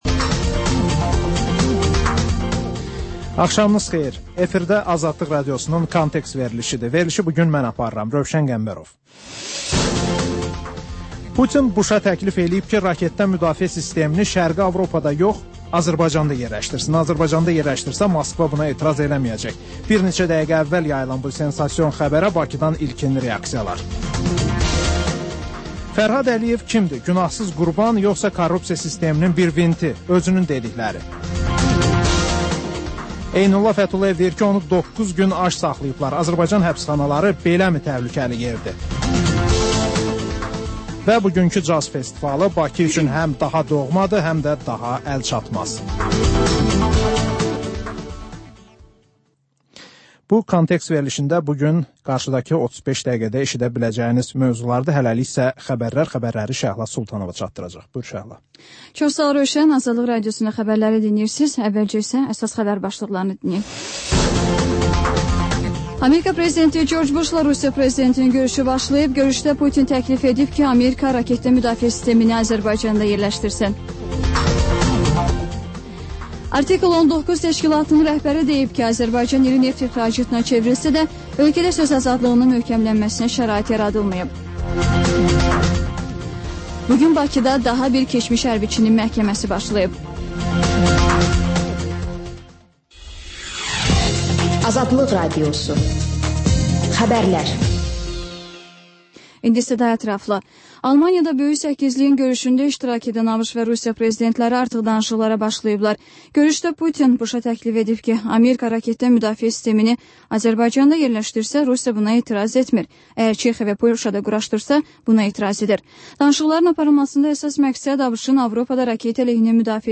Xəbərlər, müsahibələr, hadisələrin müzakirəsi, təhlillər, sonra TANINMIŞLAR rubrikası: Ölkənin tanınmış simalarıyla söhbət